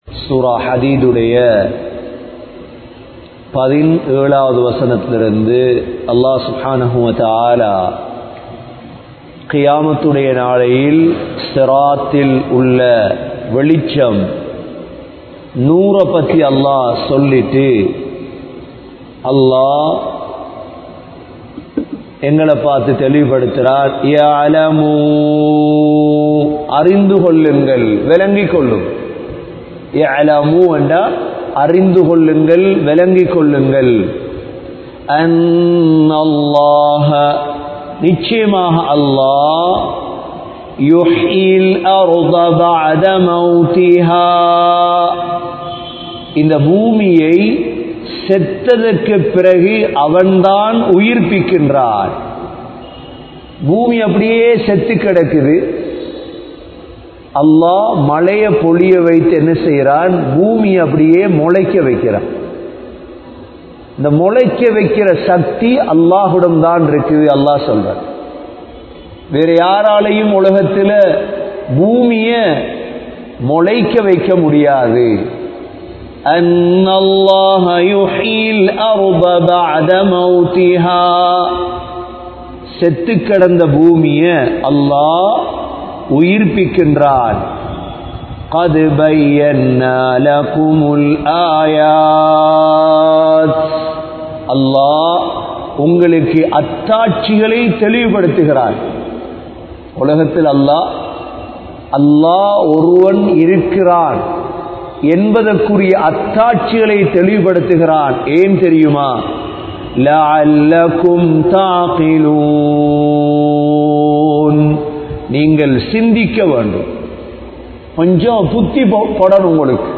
Surah AL Hadid(Part 04) Thafseer Lesson 115 | Audio Bayans | All Ceylon Muslim Youth Community | Addalaichenai
Grand Jumua Masjith